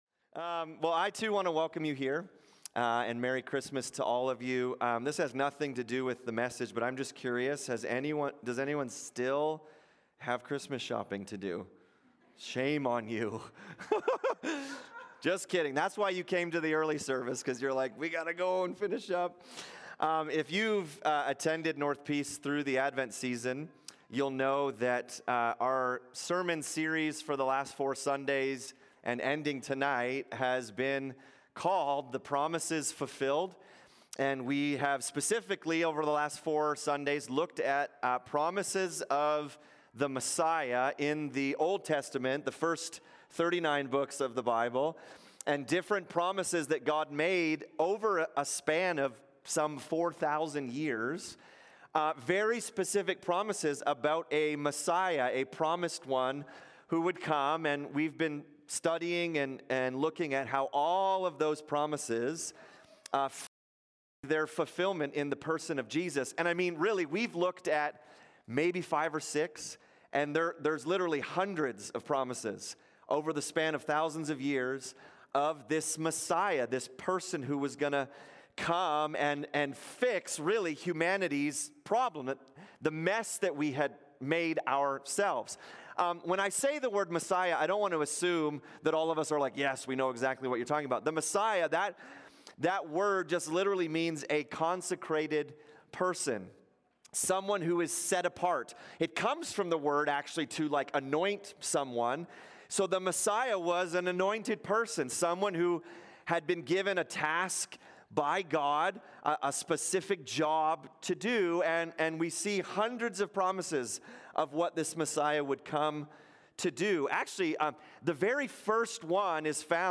Sermons | North Peace MB Church
In this Christmas Eve message we wrap up our advent series looking at Isaiah 7 and Isaiah 9. The Messiah is described as God himself! Why did Jesus need to be fully God and fully man?